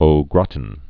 (ō grätn, grătn, gră-tăɴ)